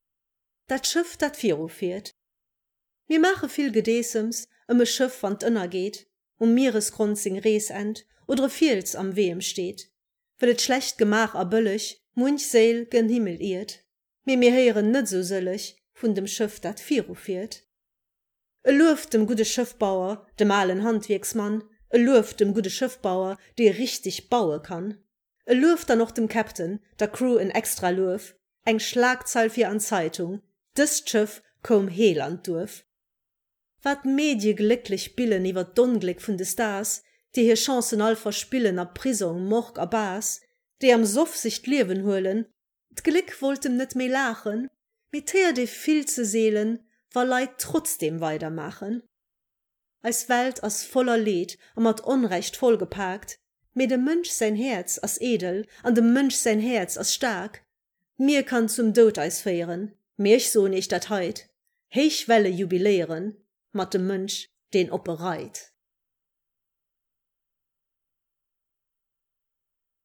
geschwate Versioun.